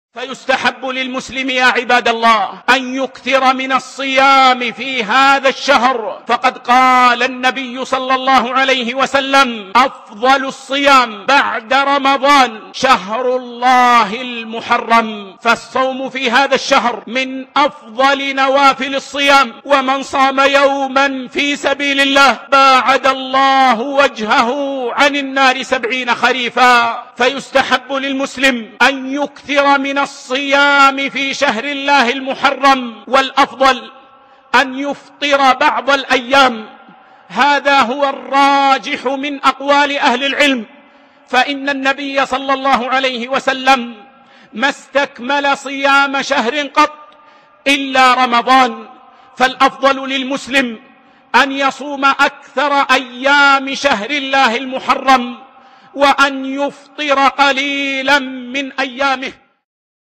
خطبة بعنوان : (الصيام في شهر الله المحرم).